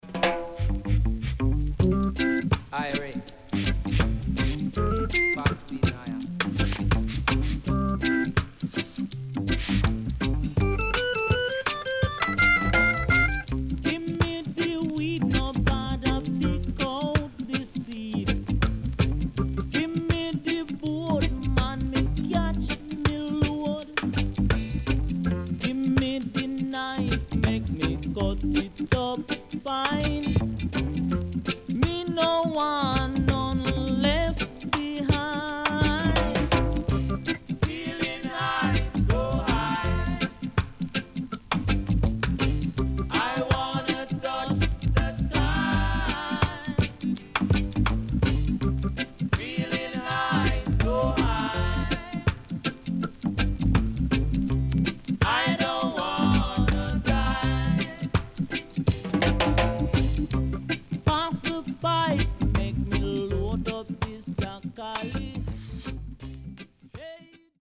HOME > REGGAE / ROOTS  >  FUNKY REGGAE
SIDE A:少しチリノイズ入ります。